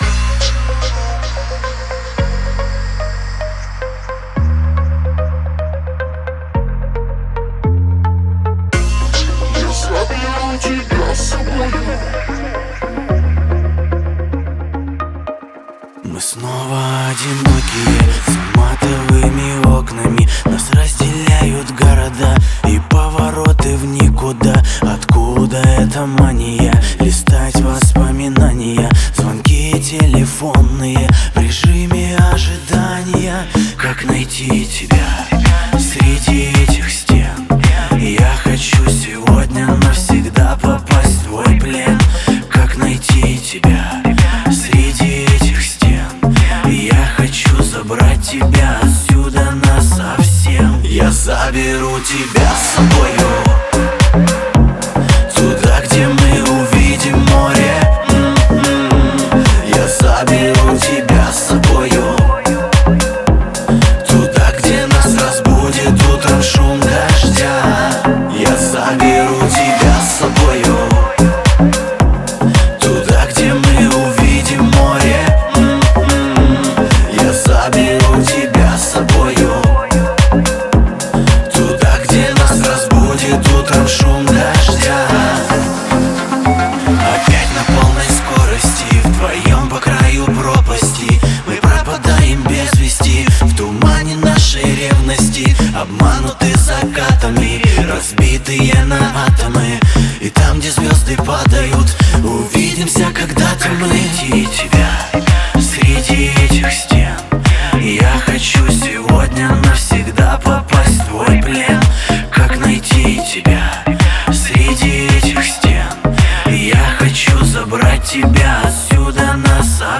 мелодичный вокал и выразительные аранжировки